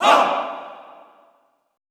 MALE HAH  -L.wav